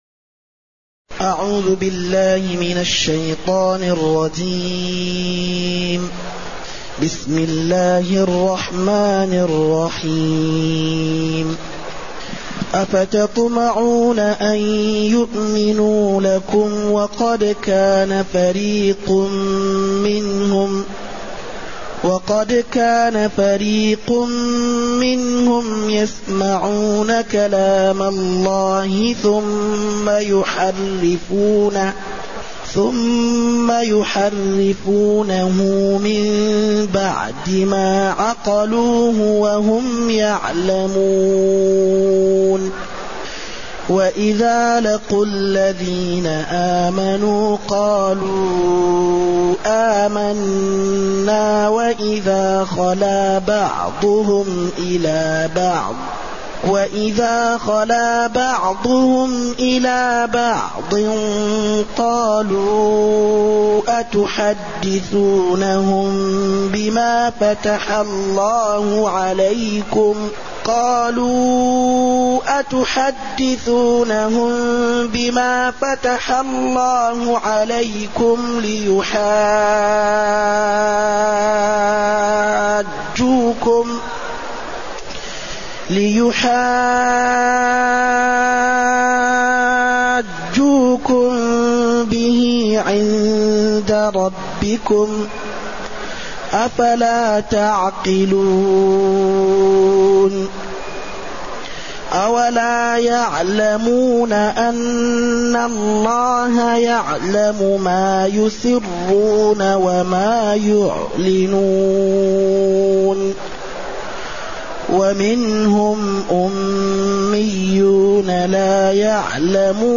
تاريخ النشر ١٤ محرم ١٤٢٨ هـ المكان: المسجد النبوي الشيخ